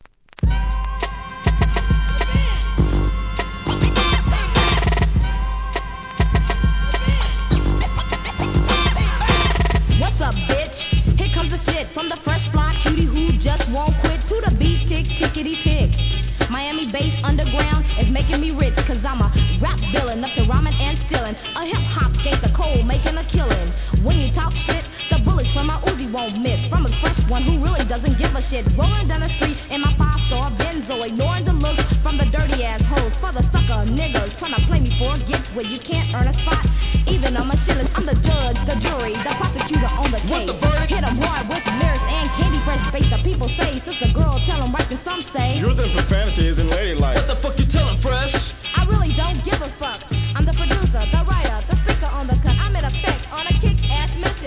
HIP HOP/R&B
1989年、マイアミ産フィメールRAP!!